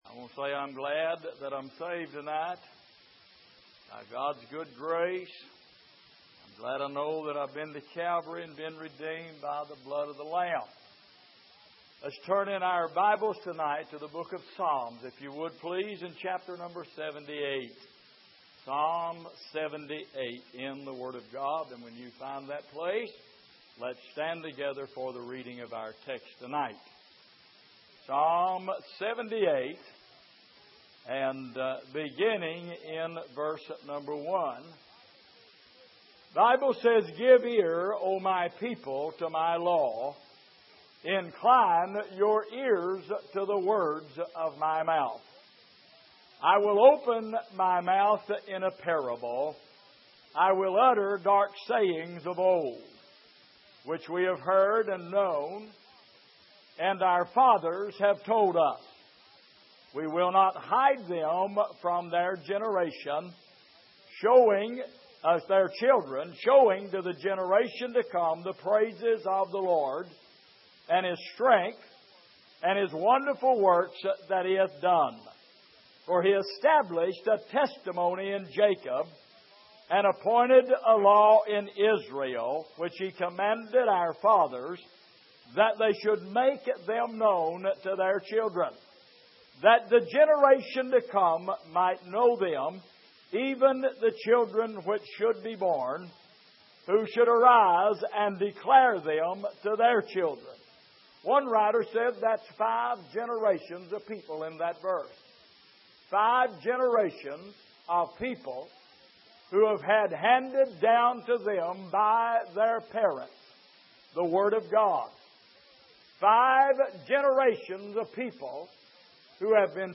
Passage: Psalm 78:1-8 Service: Sunday Evening